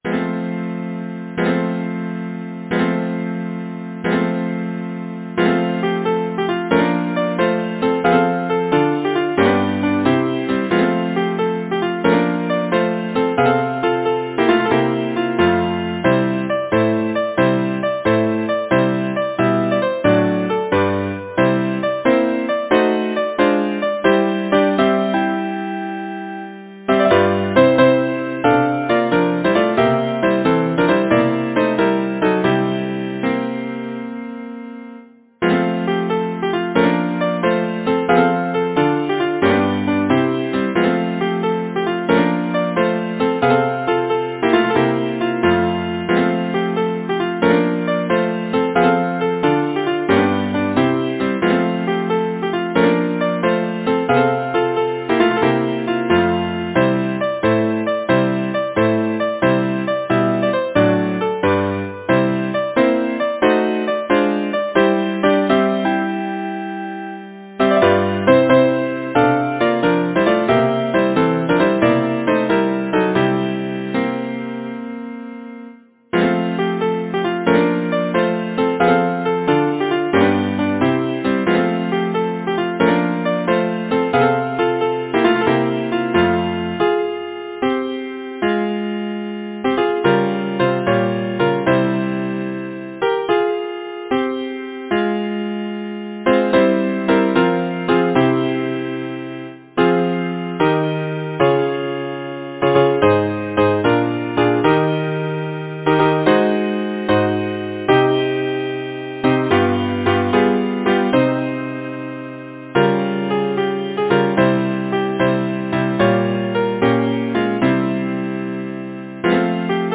Title: The Kerry Dance Composer: James Lynam Molloy Arranger: William Rhys-Herbert Lyricist: James Lynam Molloy Number of voices: 4vv Voicing: SATB Genre: Secular, Partsong
Language: English Instruments: A cappella